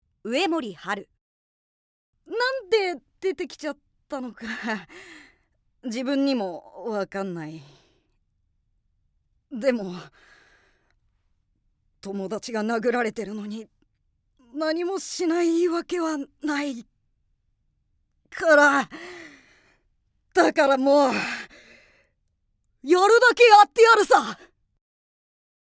◆友情に熱い少年(ヘタレ)◆